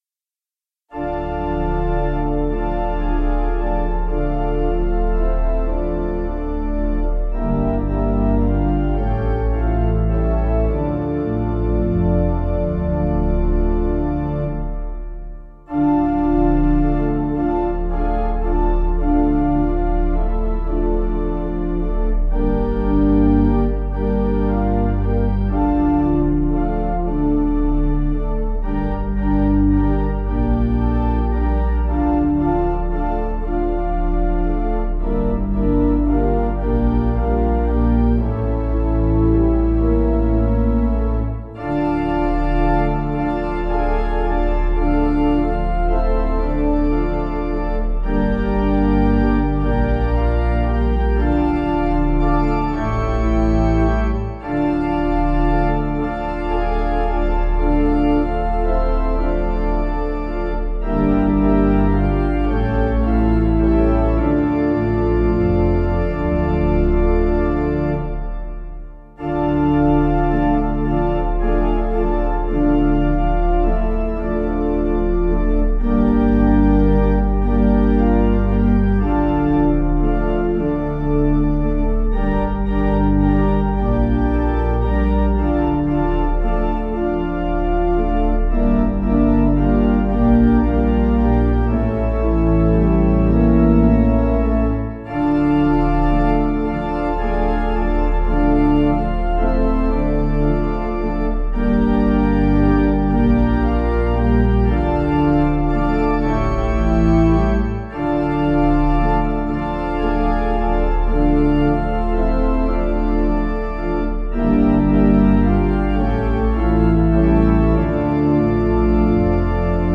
Softer Version   1.2mb